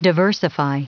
Prononciation du mot diversify en anglais (fichier audio)
Prononciation du mot : diversify